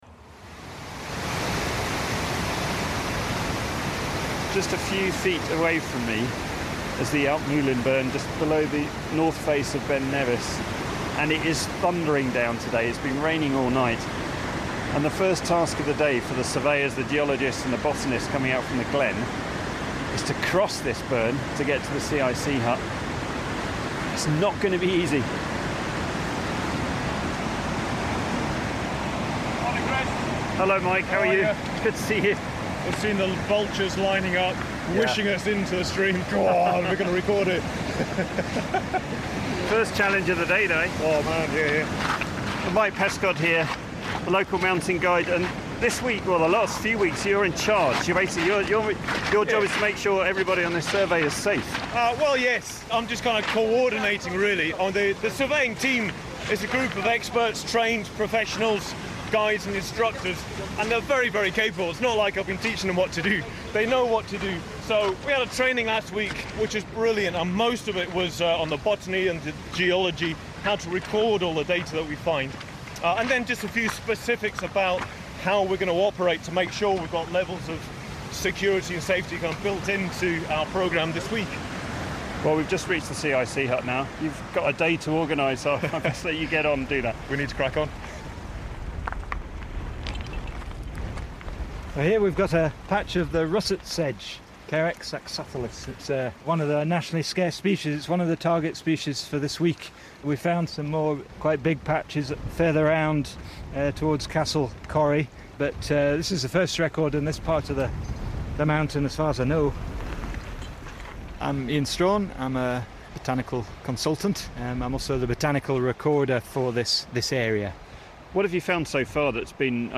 A day with the botanists and mountaineers surveying the north face of Britain's highest mountain.